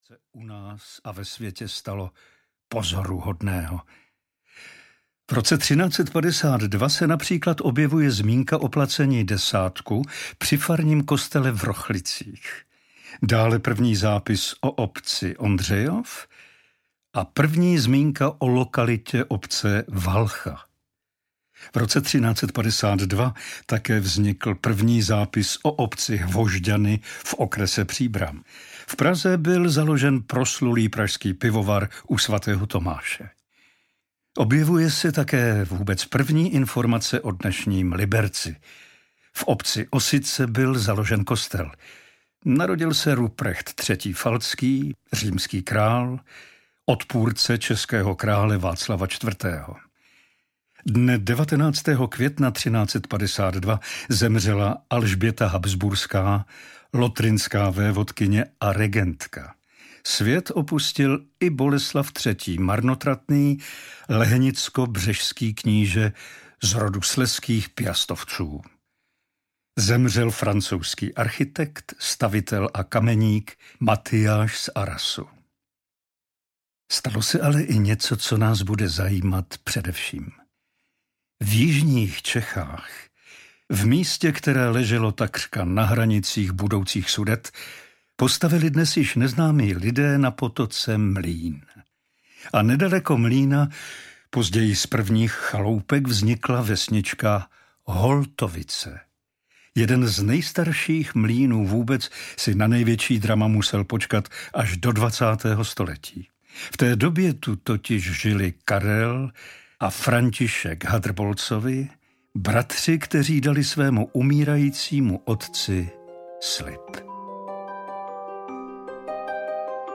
Mlýn audiokniha
Ukázka z knihy
• InterpretPavel Soukup